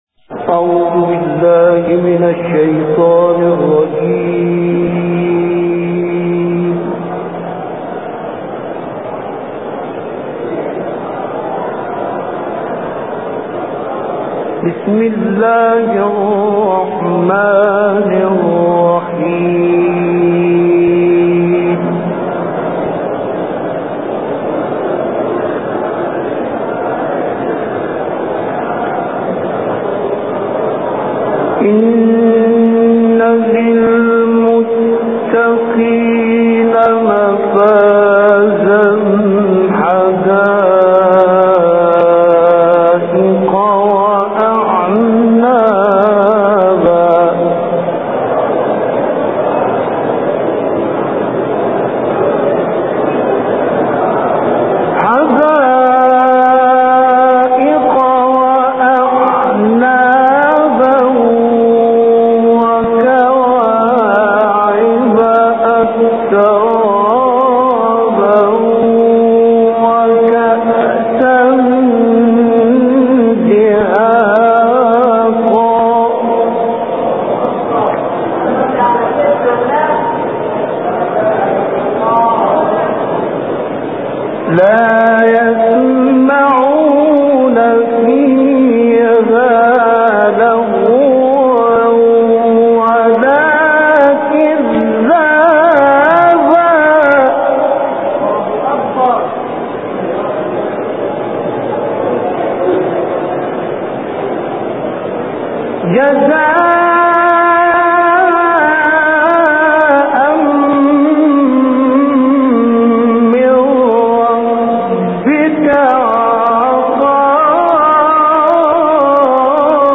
تلاوت مجلسی